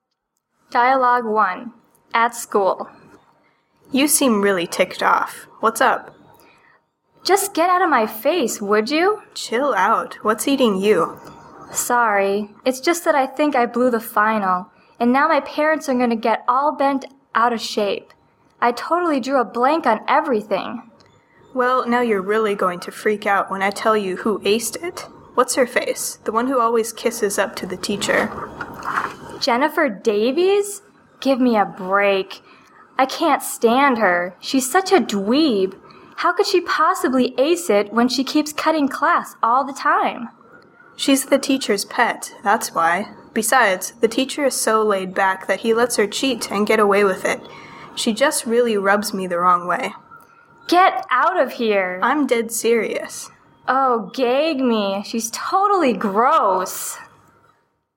مکالمات محاوره ای